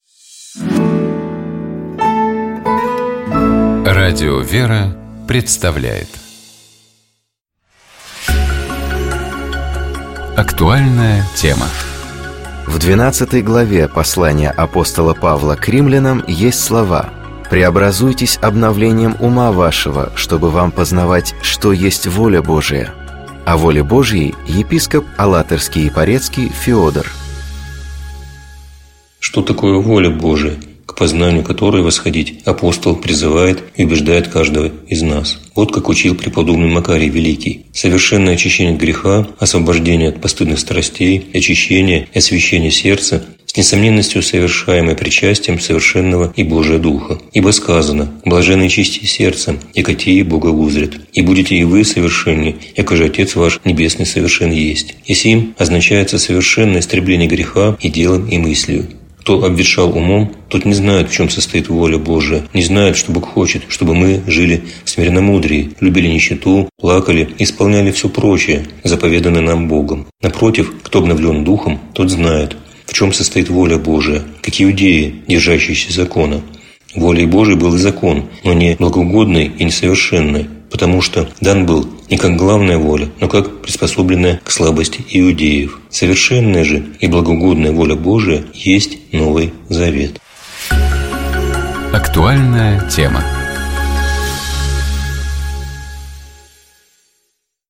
О воле Божьей, — епископ Алатырский и Порецкий Феодор.